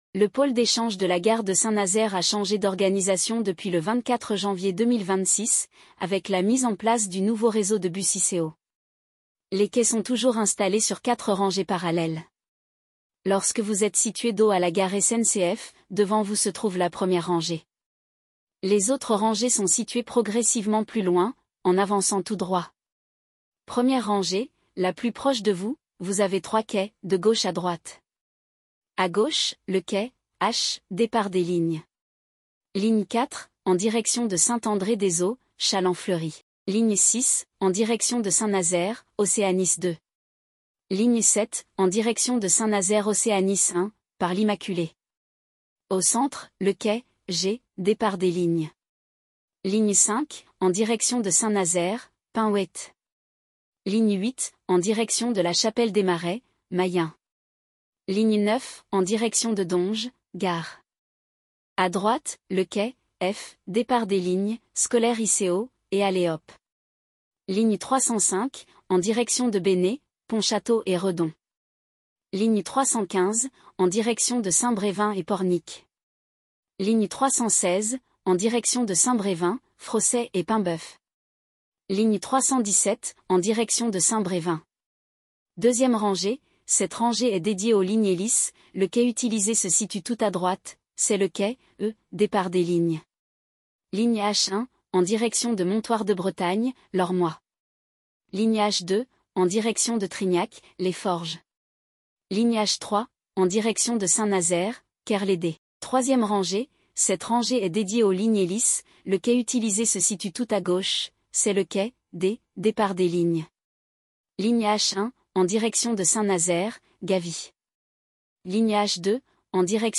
La synthèse vocale du pôle d’échange Gare de Saint-Nazaire est disponible